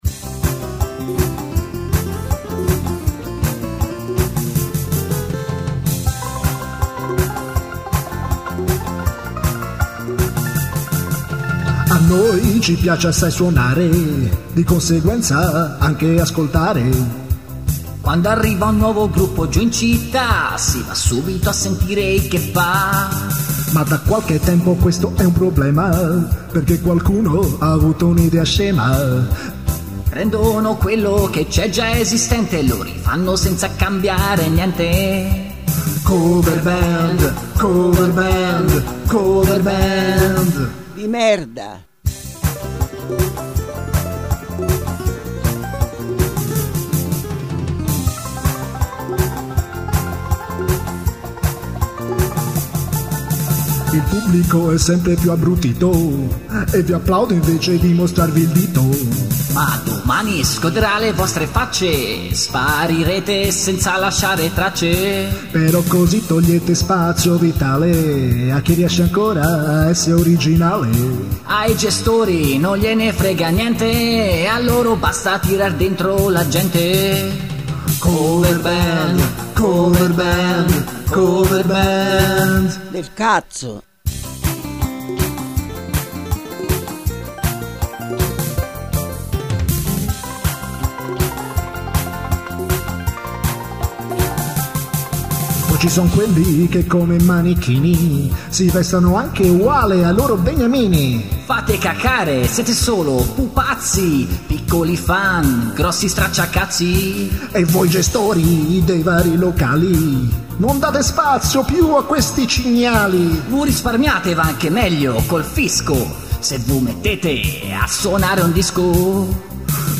un duetto
Uno sfogo un po' avvelenato, non cantato male, ascoltabile.